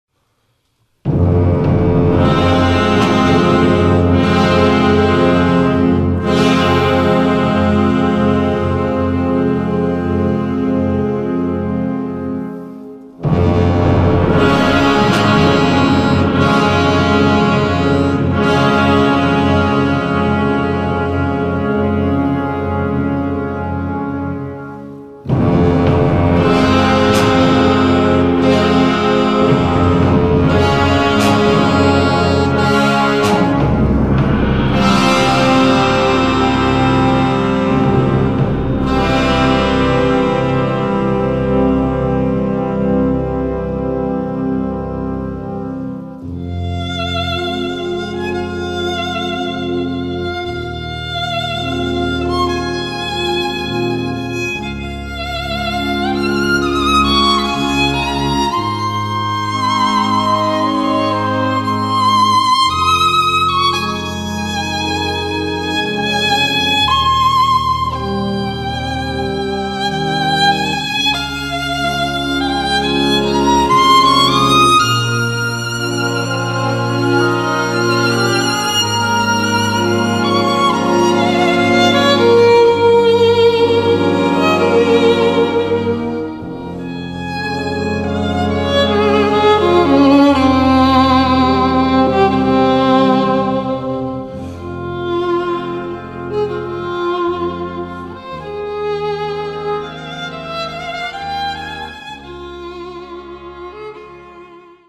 Voicing: Clarinet and Concert Band